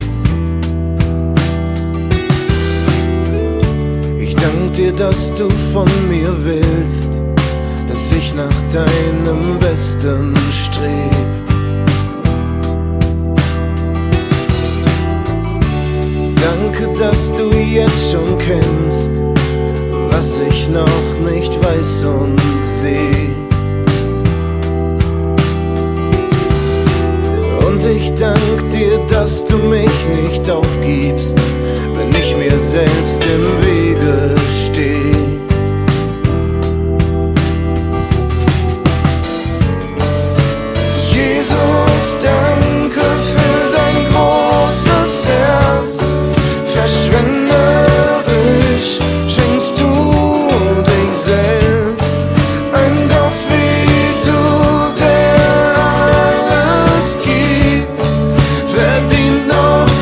Choräle & Heilslieder, Worship 0,99 €